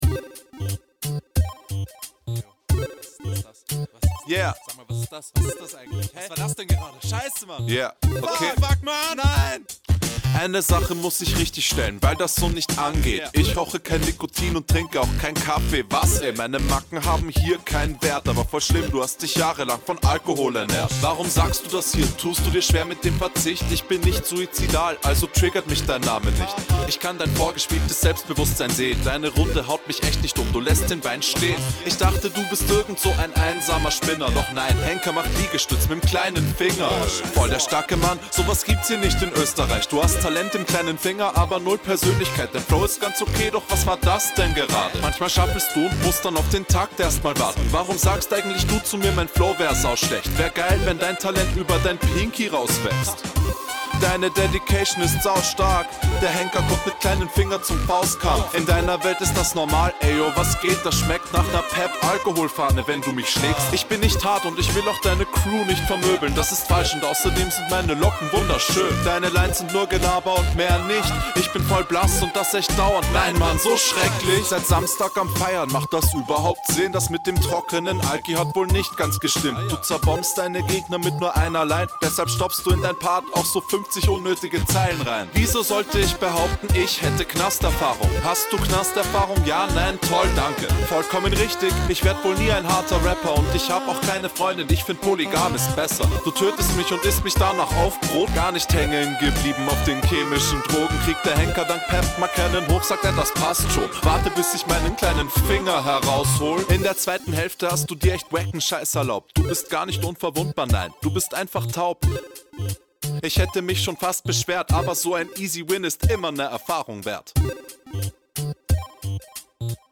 Du kommst mit deutlich weniger Power rein als dein Gegner.